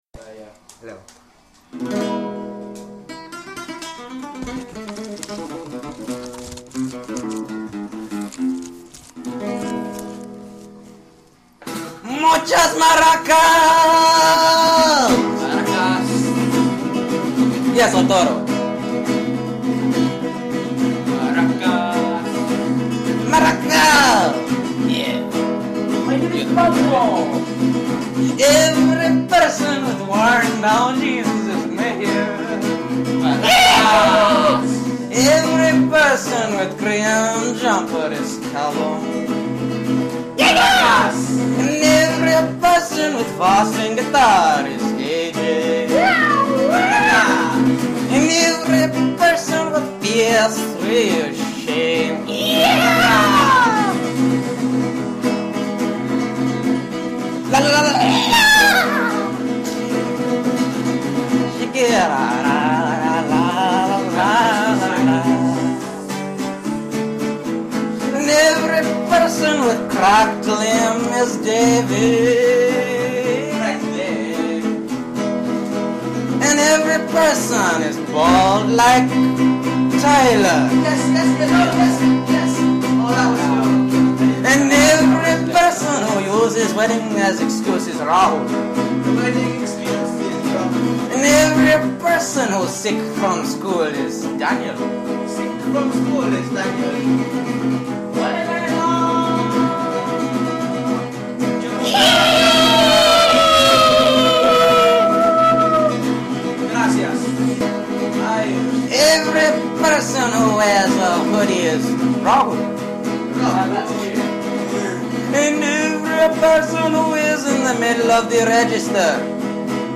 Week 02 - Spoof Songs
The song is called "Paratas" and is strongly based in beautiful Spanish guitar
The lyrics were mostly improvised from the second line, and was simply following a simple formula and would probably be more understa